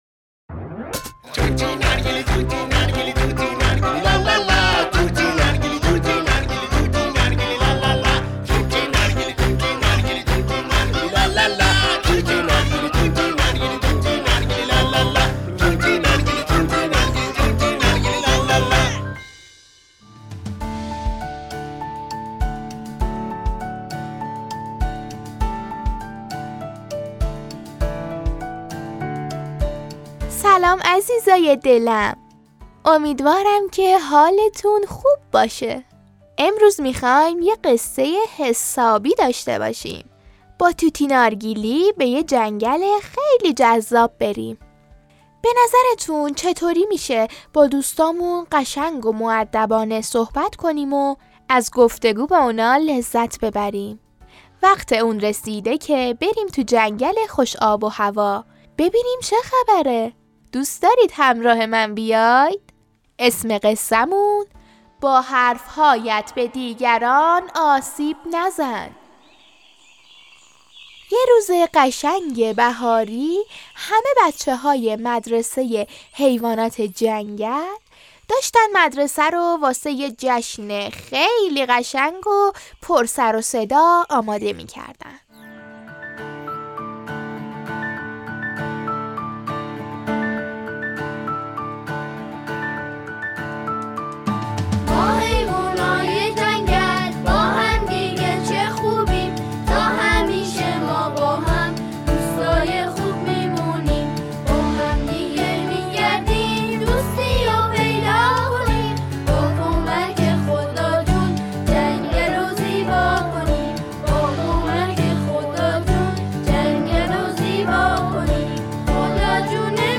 حسادت در کودکان - (با اجرا و خوانندگی کودکان) - وقتی در جنگل حیوانات، یک مار بجای رفاقت با دوستانش شروع به حسادت و توهین به اونها میکنه چه اتفاقی می افته؟!...